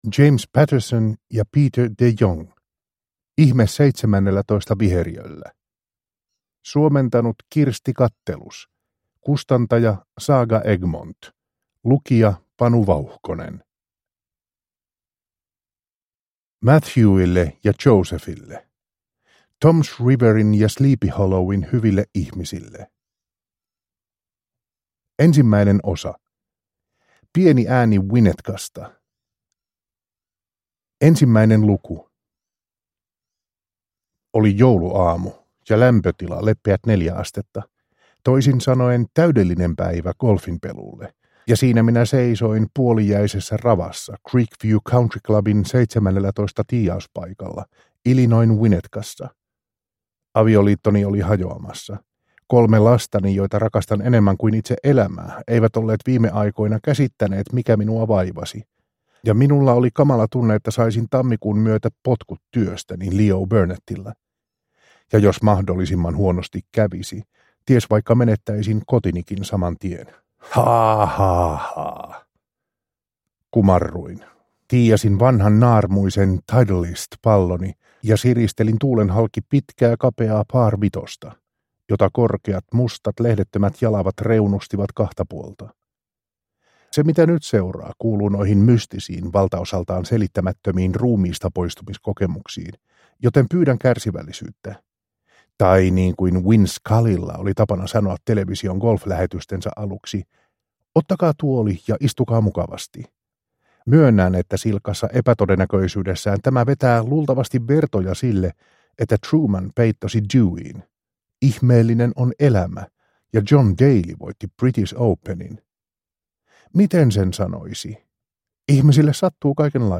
Ihme 17. viheriöllä – Ljudbok